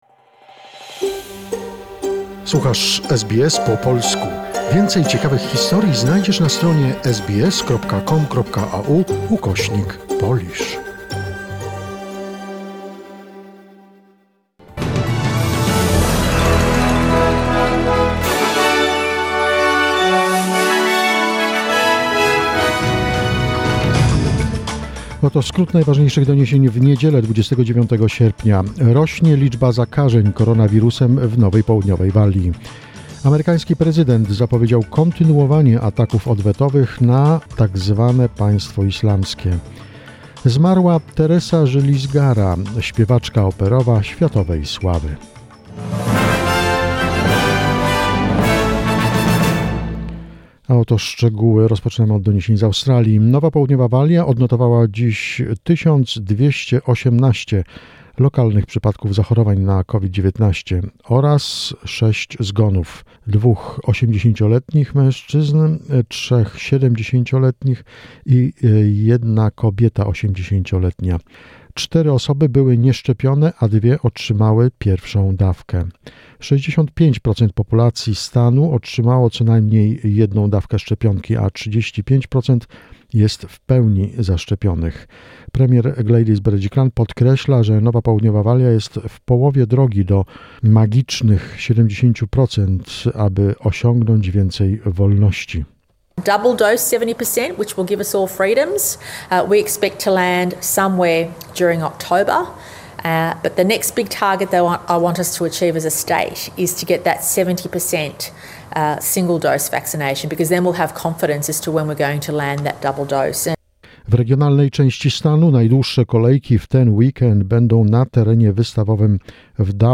SBS News in Polish, 29 August 2021